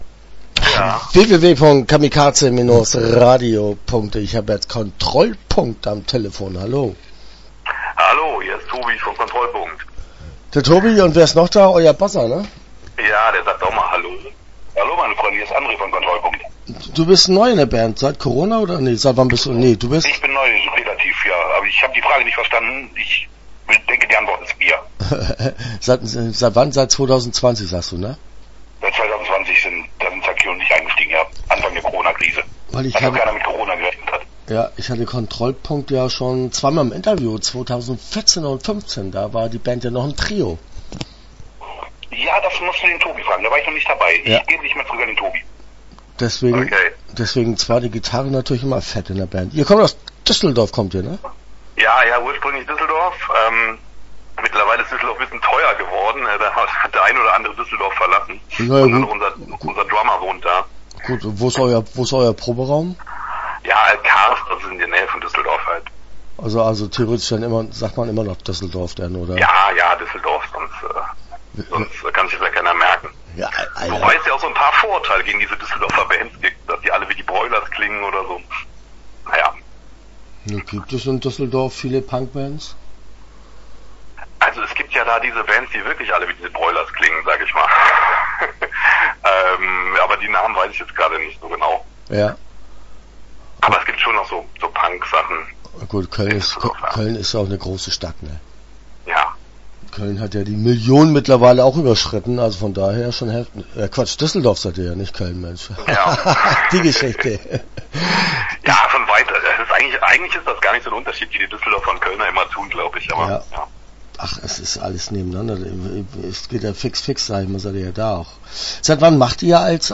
Start » Interviews » Kontrollpunkt